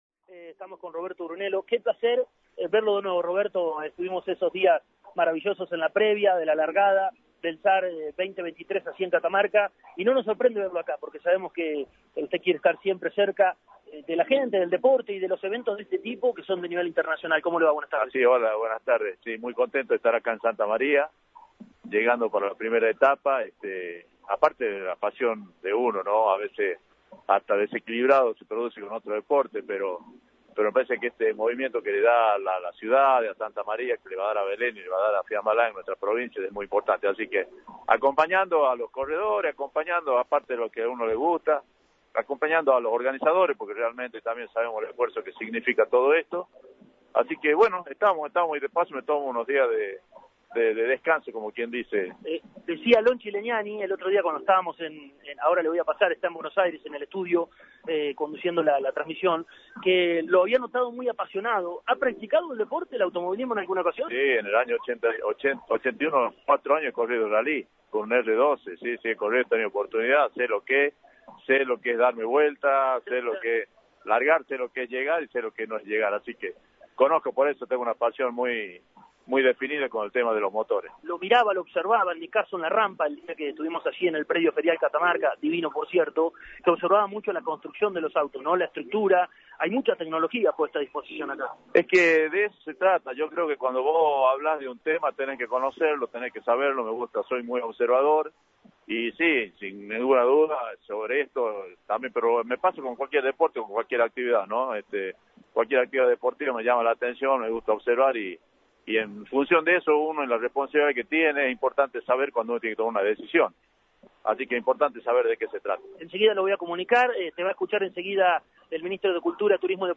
De eso se trata cuando decidimos hacer este tipo de eventos”, expresó el Ministro de Cultura, Turismo y Deporte de Catamarca, Roberto Brunello, en Campeones Radio.